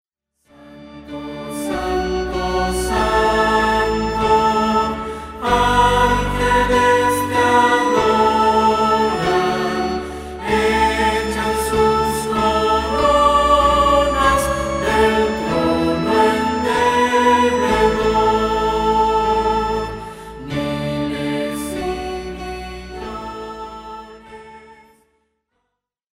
que presenta himnos tradicionales con un enfoque fresco